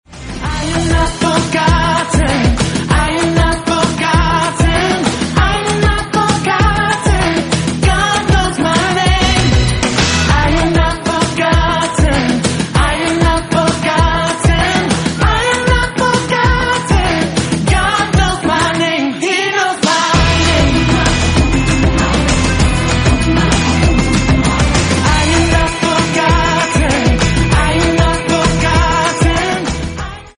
Kids live worship
Die Kinder-Lobpreis-CD, die in keinem
• Sachgebiet: Kinderlieder